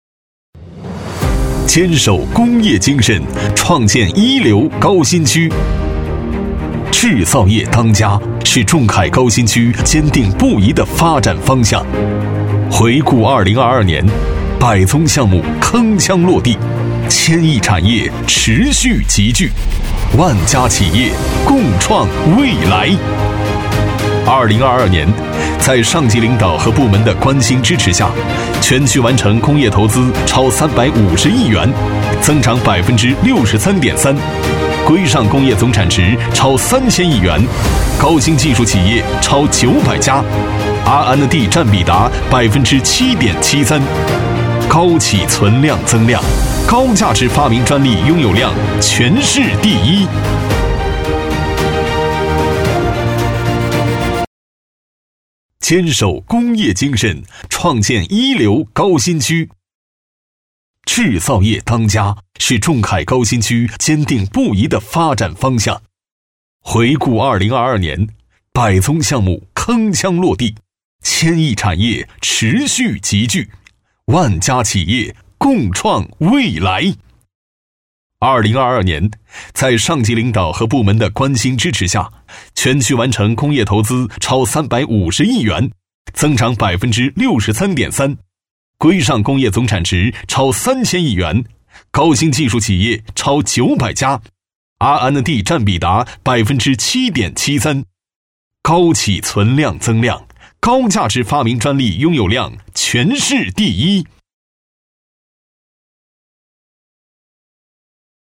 男3号（品质、洪亮）
男3-专题激情铿锵有力-坚守工业精神
男3-专题激情铿锵有力-坚守工业精神.mp3.mp3